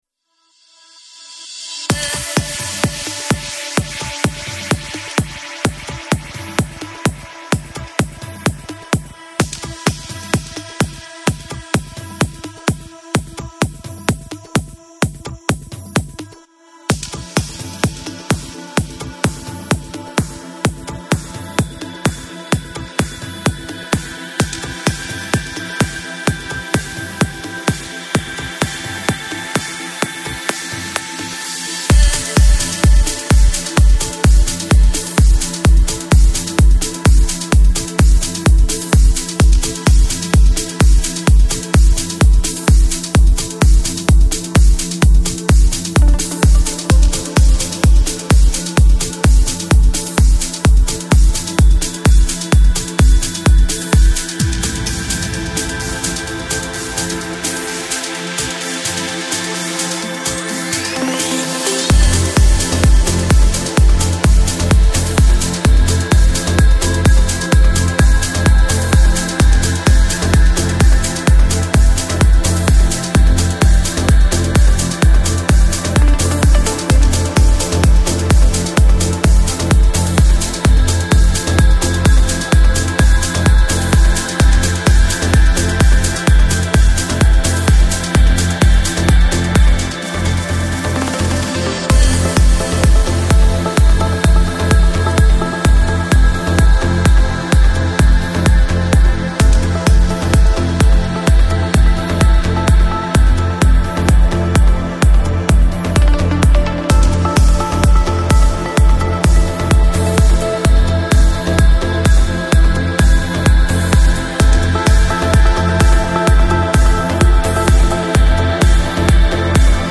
Стиль: Progressive Trance / Vocal Trance